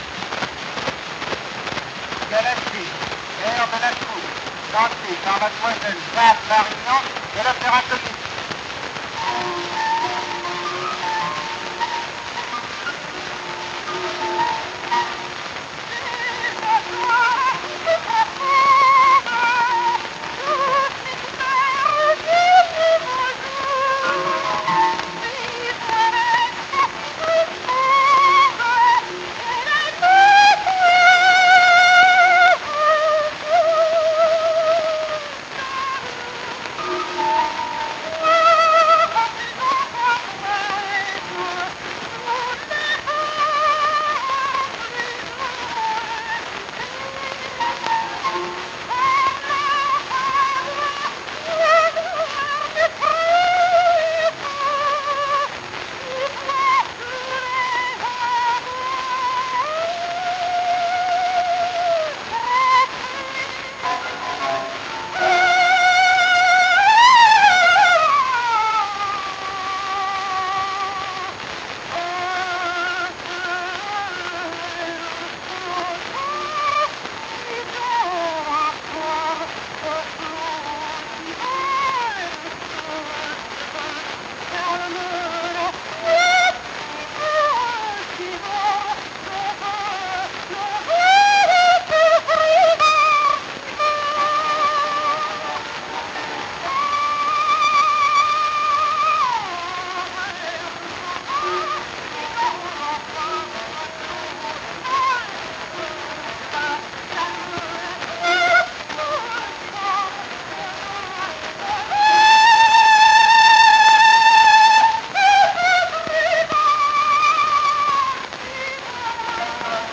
Air de la coupe, zweiter Teil. Sopran mit Klavierbegleitung.